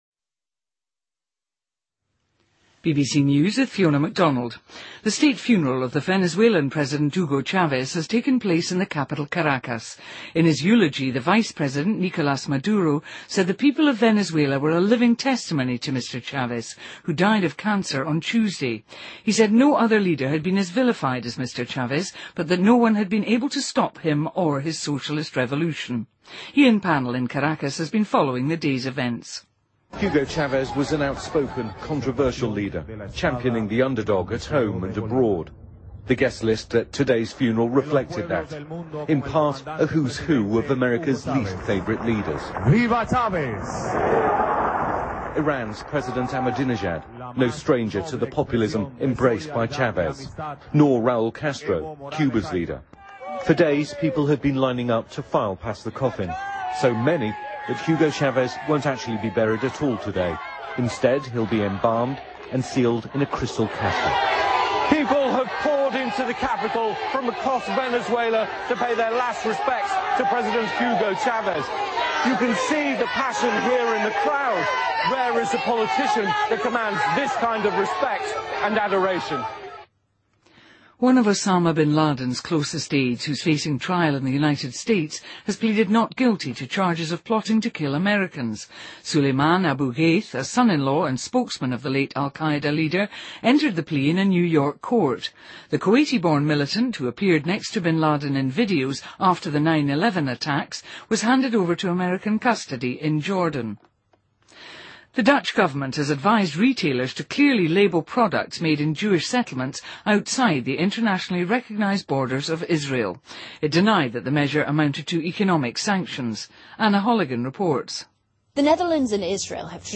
BBC news,2013-03-09